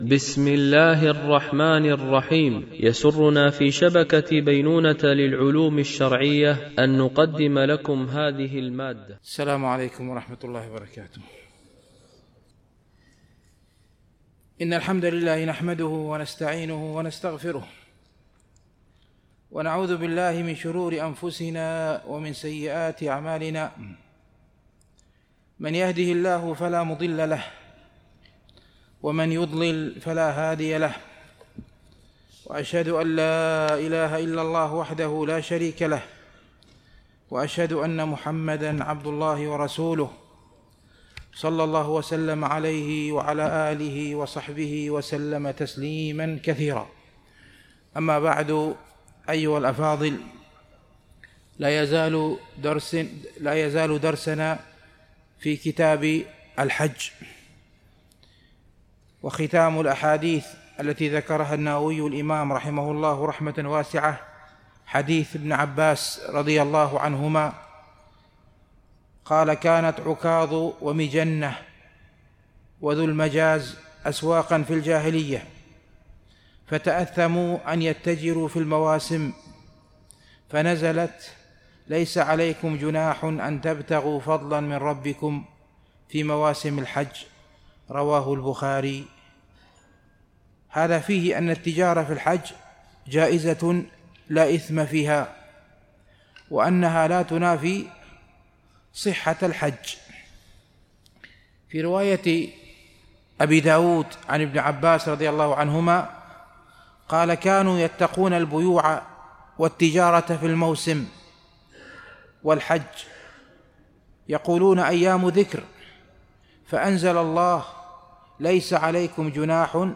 شرح رياض الصالحين – الدرس 340 ( الحديث 1292 )